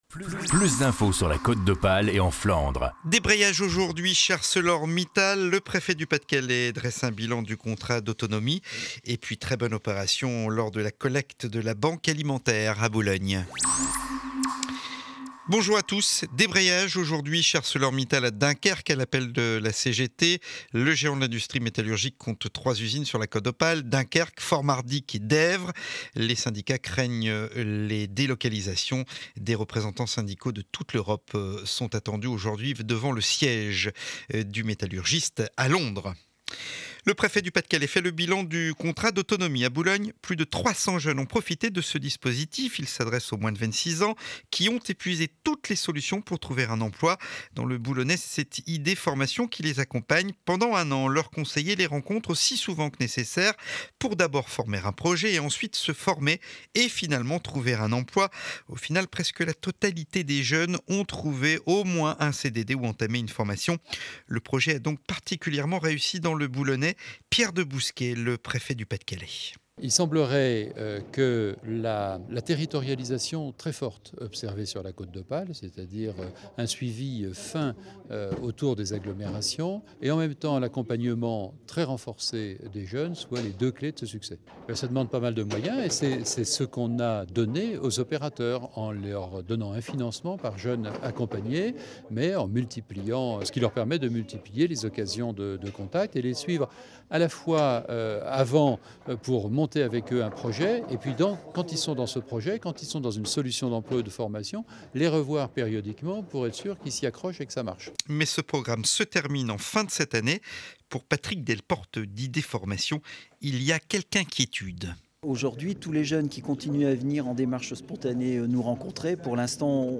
Journal de 12 du mercredi 7 décembre édition de Boulogne.